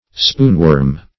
Search Result for " spoonworm" : The Collaborative International Dictionary of English v.0.48: Spoonworm \Spoon"worm`\ (sp[=oo]n"w[^u]rm`), n. (Zool.)